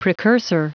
Prononciation du mot precursor en anglais (fichier audio)
Prononciation du mot : precursor